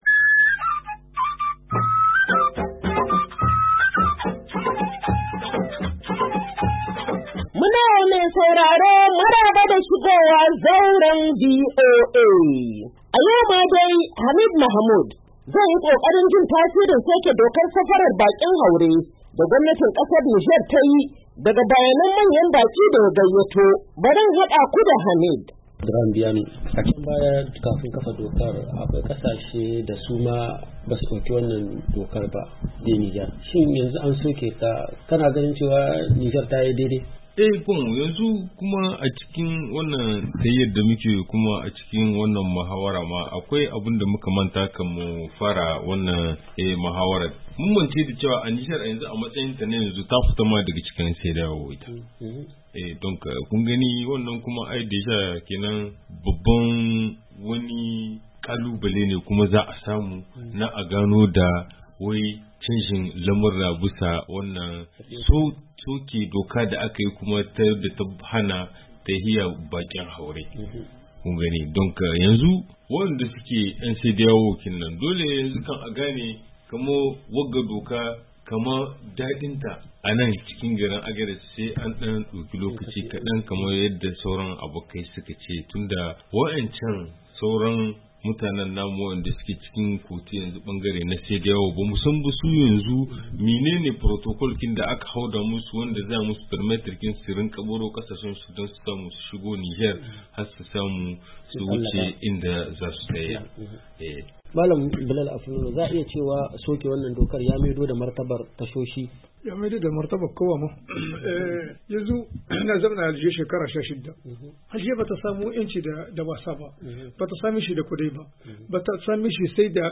Shirin Zauran VOA na wannan mako, zai kawo muku kashi na karshe na tattaunawa da bakin Zaura a jihar Agadez da ke jamhuriyar Nijar, kan soke dokar haramta safarar bakin haure da aka yi a kasar, bayan ta yi shekaru 8 tana aiwatar da dokar.